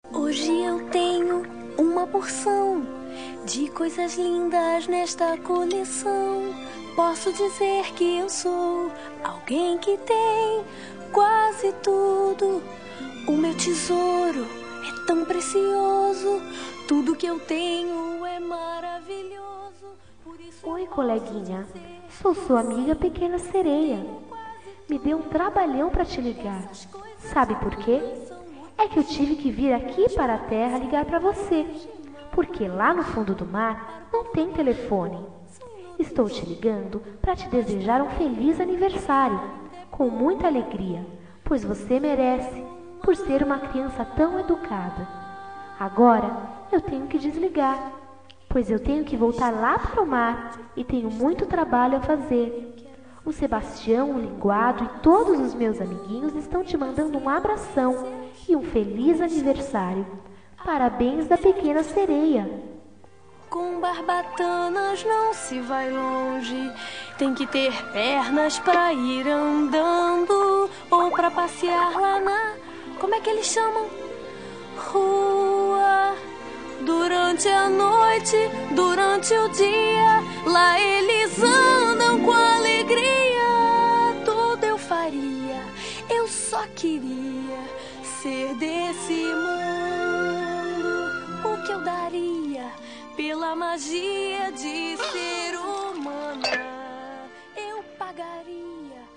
Telemensagem de Criança – Voz Feminina – Cód: 8121 – Pequena Seria
8121-aniv-infantil-pequena-sereia.m4a